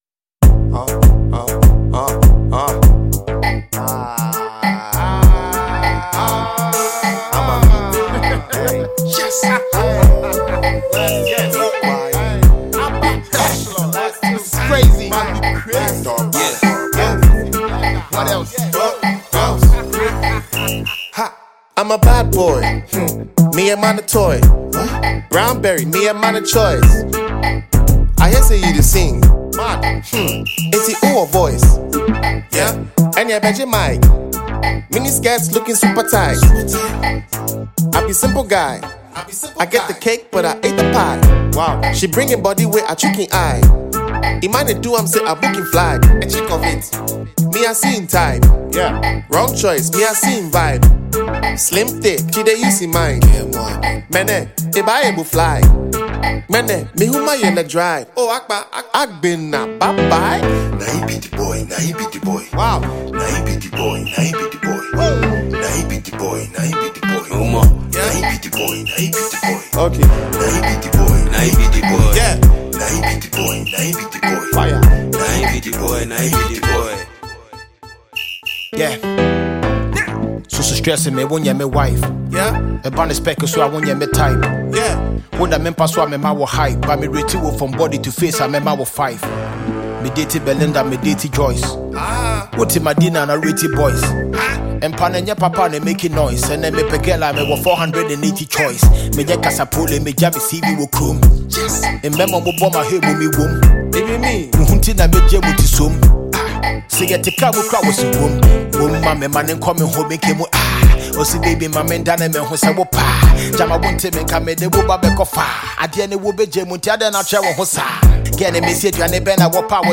explosive hip-hop anthem